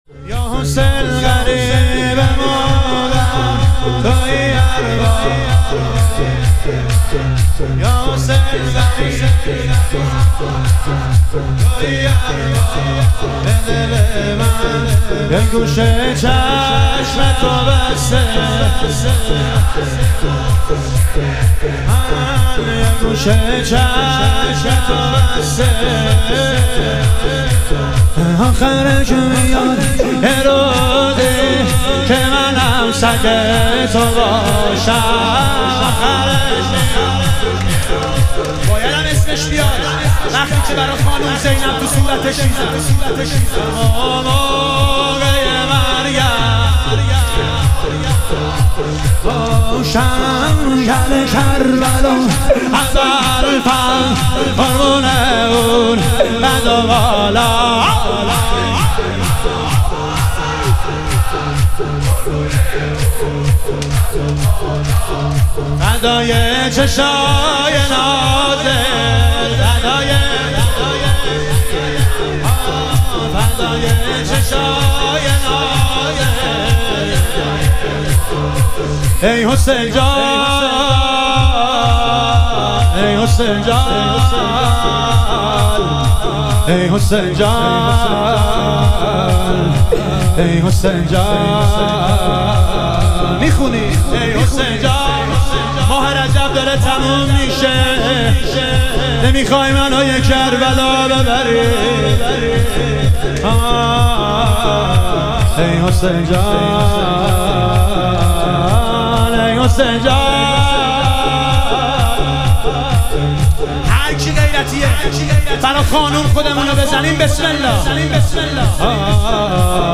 شهادت حضرت زینب کبری علیها سلام - شور